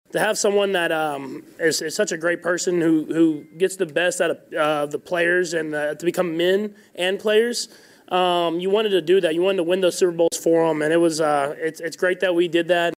Chiefs quarterback Patrick Mahomes says Andy Reid is one of the greatest head coaches of all time.